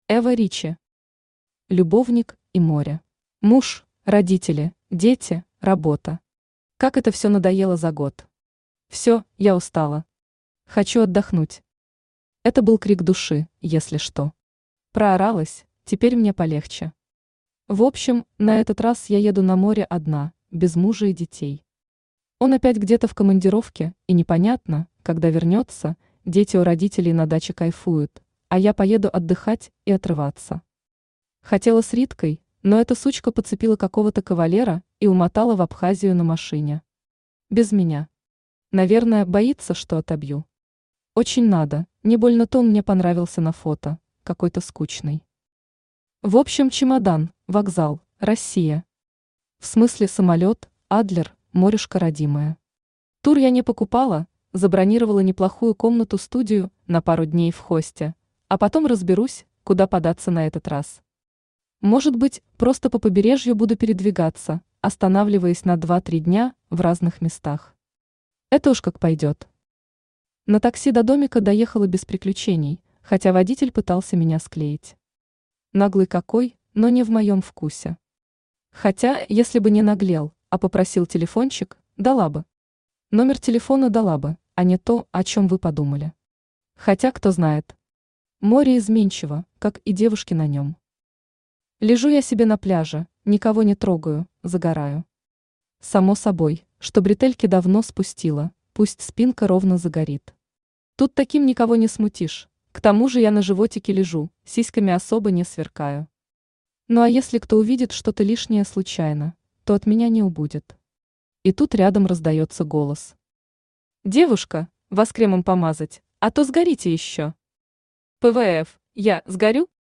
Aудиокнига Любовник и море Автор Эва Ричи Читает аудиокнигу Авточтец ЛитРес.